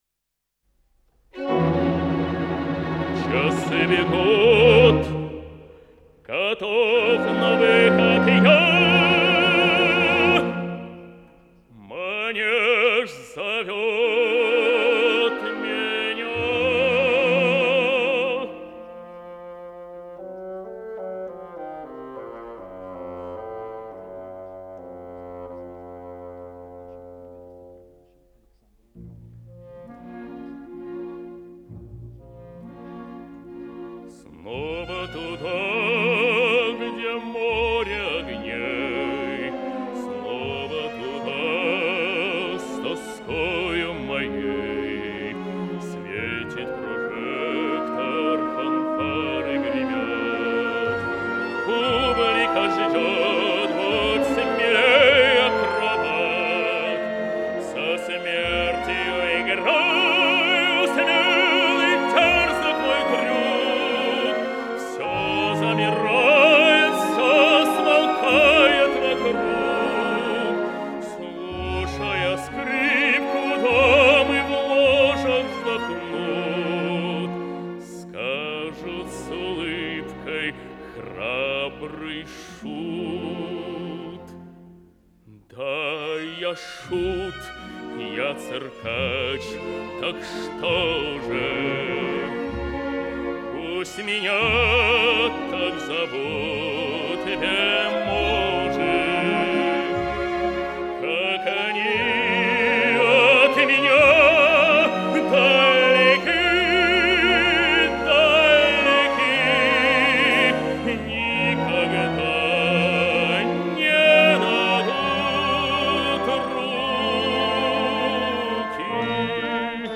Ария Мистера Икса Оперетта Имре Кальмана "Принцесса цирка" Запись радио Санкт-петербурга.